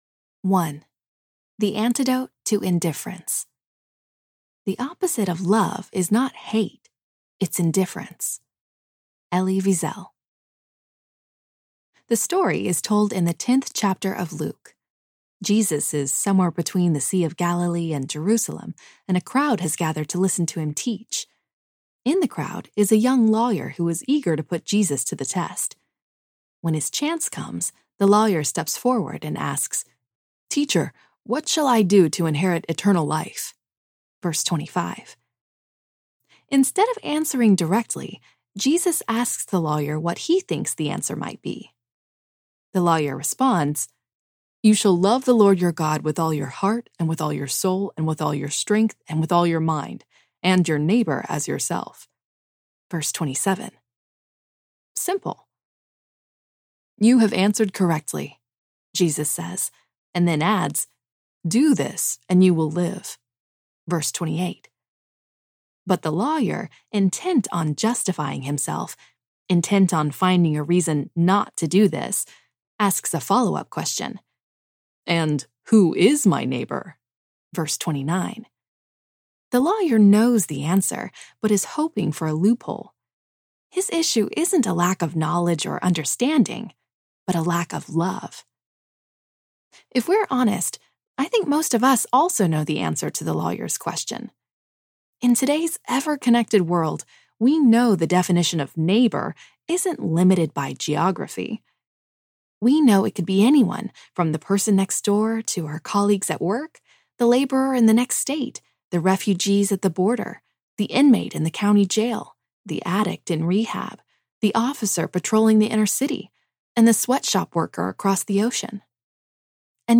Loving Well in a Broken World Audiobook
Narrator
7.7 Hrs. – Unabridged